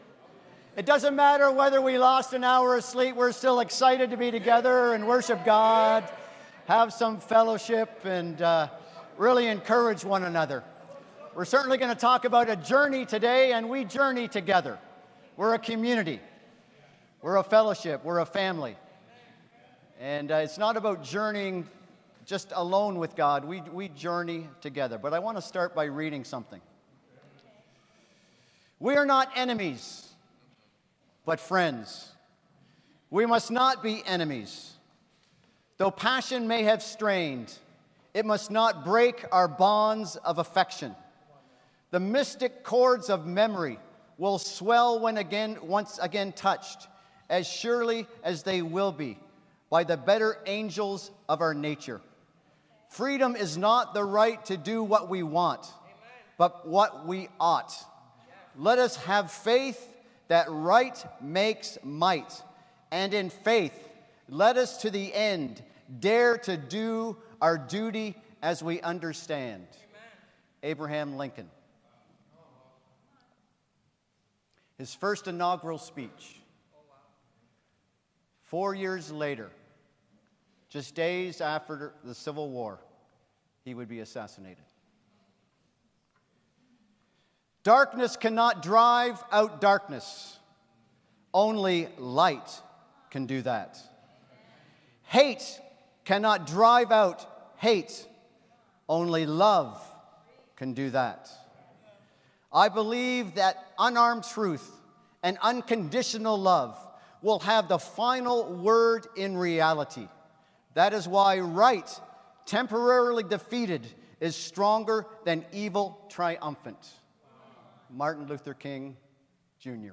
Emmaus: Our Journey of Faith - Ottawa Church of Christ